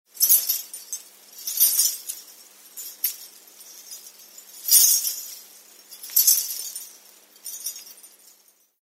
Danza árabe, bailarina haciendo el movimiento infinito horizontal 02
moneda
Sonidos: Acciones humanas